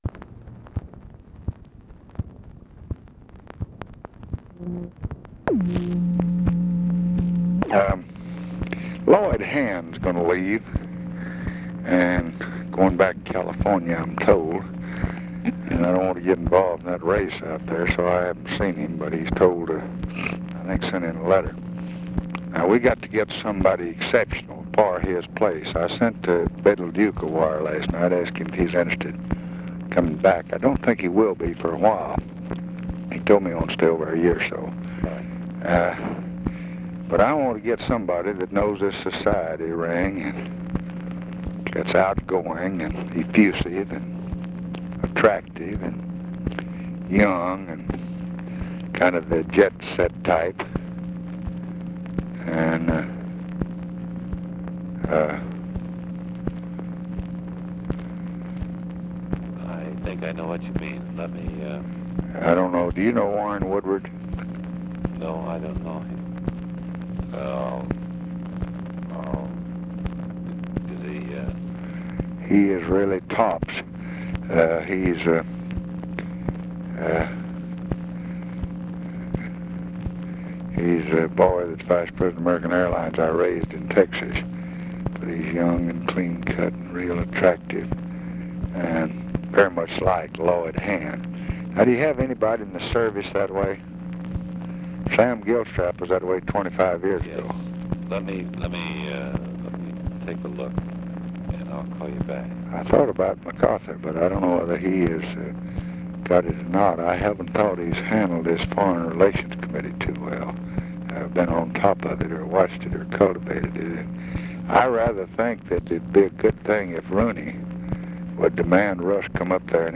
Conversation with WILLIAM CROCKETT, March 18, 1966
Secret White House Tapes